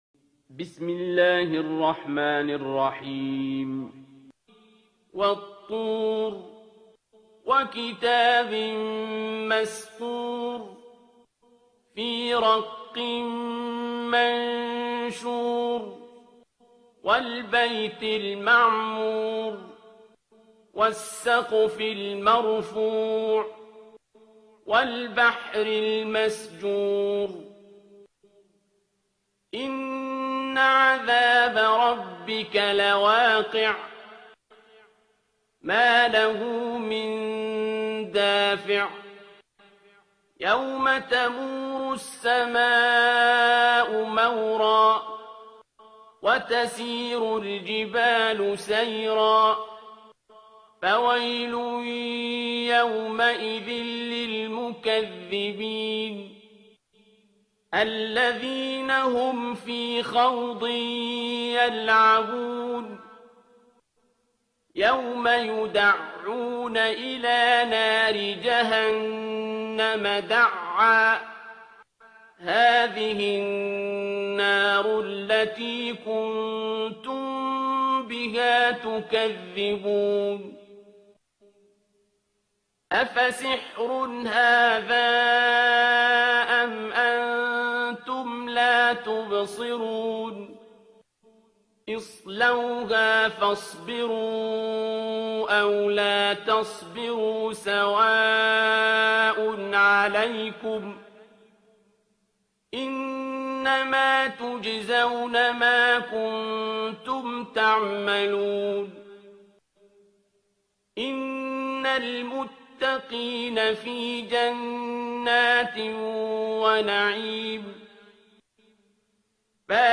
ترتیل سوره طور با صدای عبدالباسط عبدالصمد